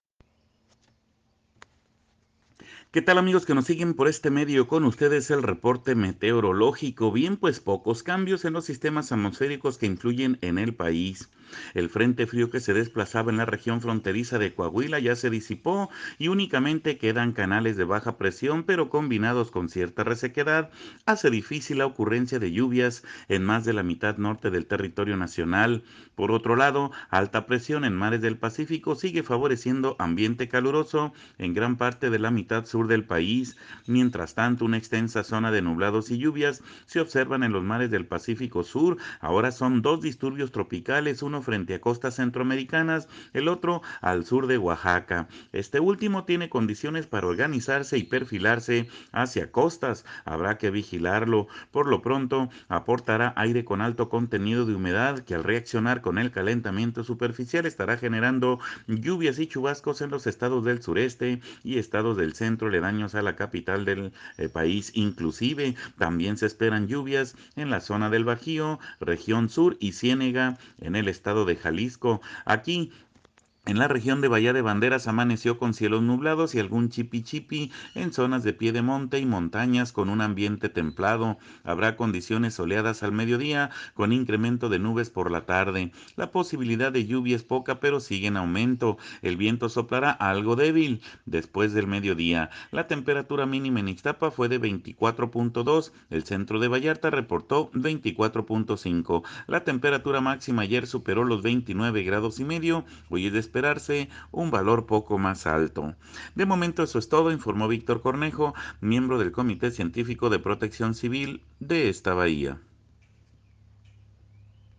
AUDIO: escuche al meteorólogo